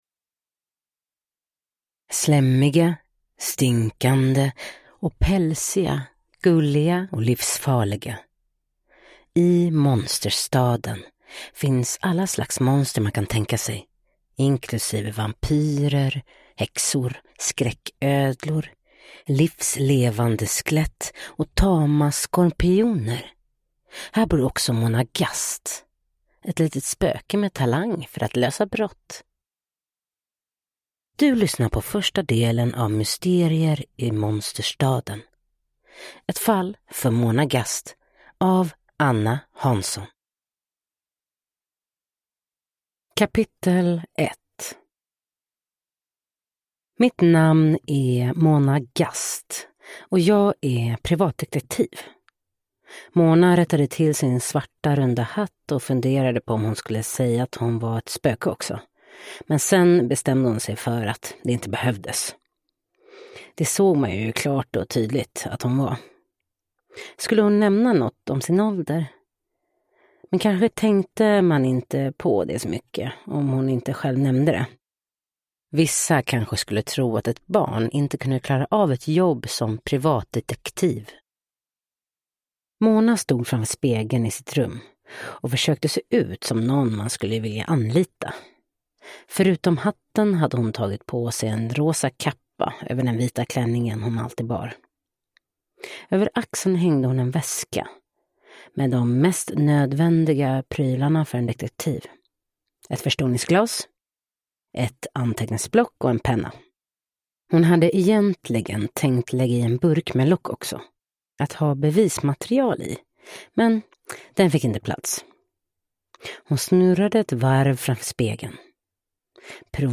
Ett fall för Måna Gast – Ljudbok